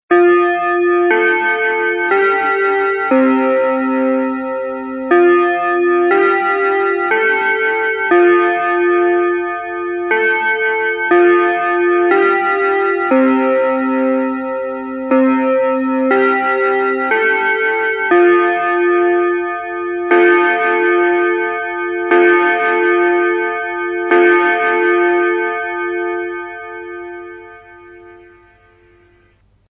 Sonnerie Westminster
Westminster.mp3